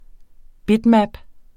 Udtale [ ˈbidˌmab ]